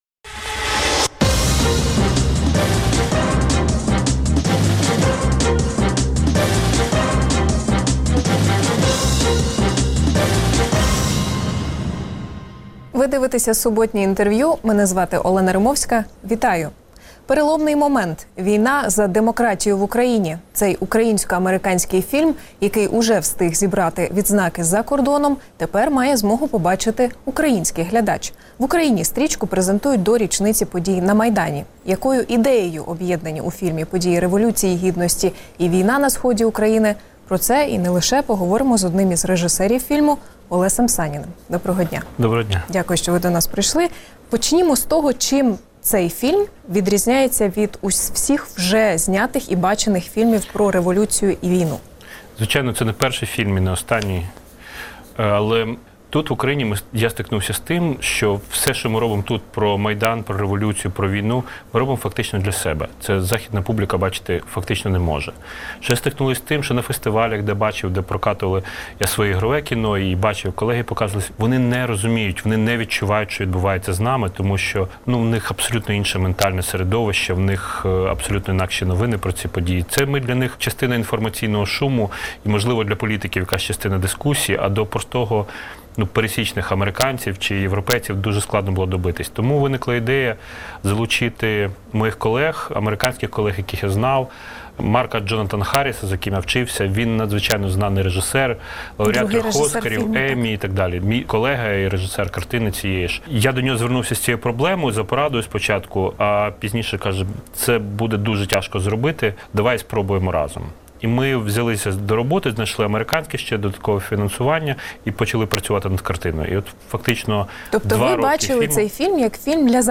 Суботнє інтерв’ю | Революція і війна через об'єктив кінокамери